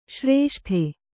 ♪ śrēṣṭhe